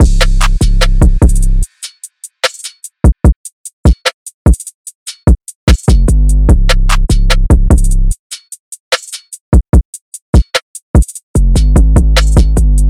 DRUM LOOPS
Canton (148 BPM – Cm)